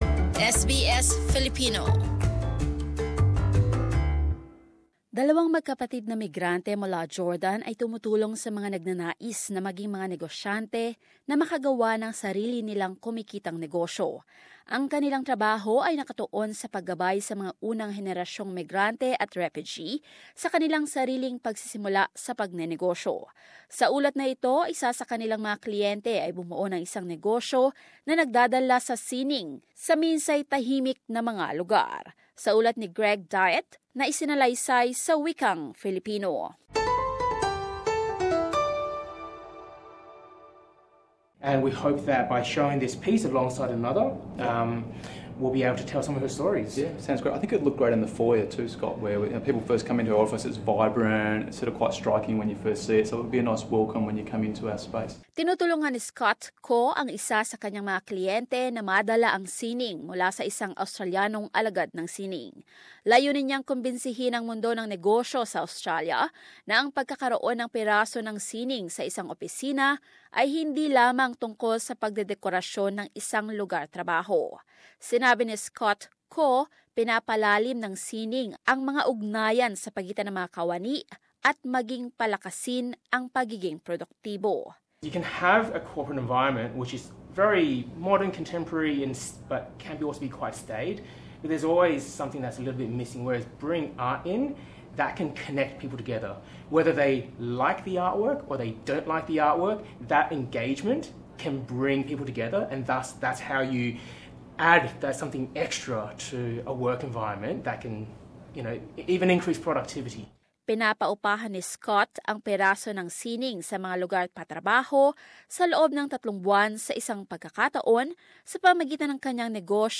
As this report, one of their clients has developed a businesss that brings art to sometimes staid environments.